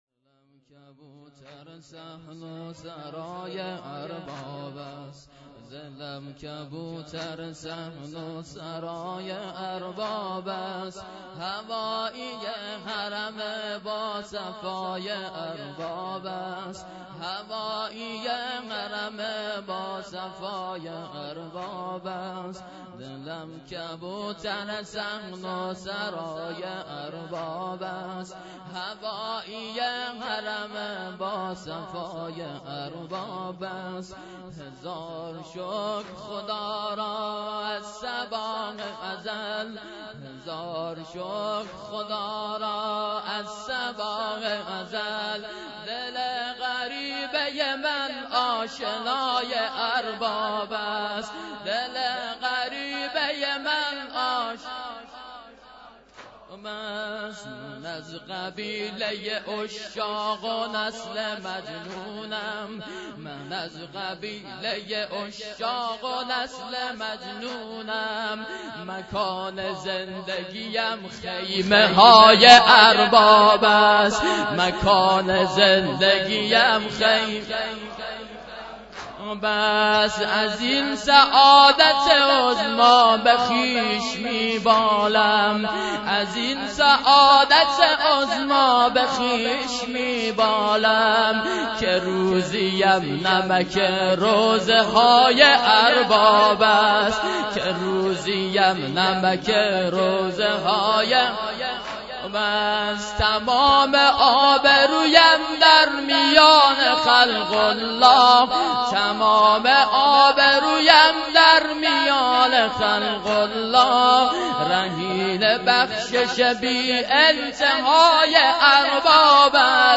محرم93 - هیات انصارالمهدی بندرامام خمینی ره